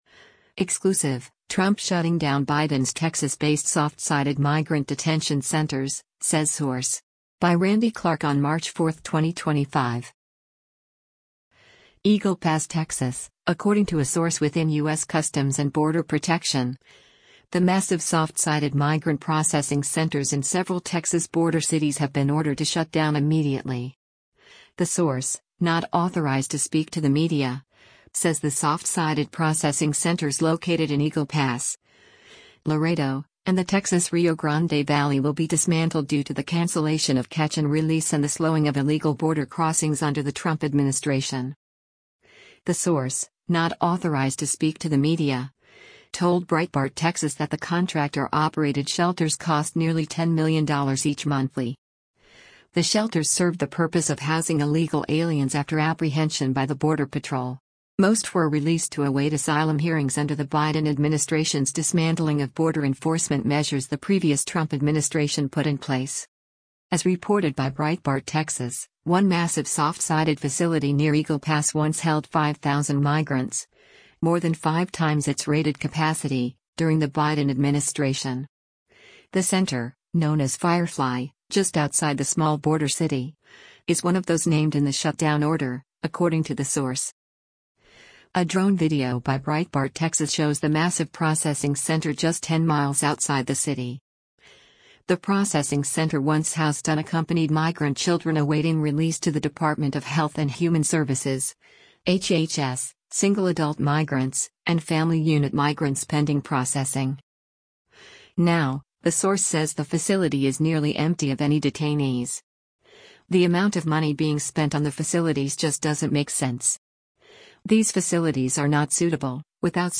A drone video by Breitbart Texas shows the massive processing center just 10 miles outside the city.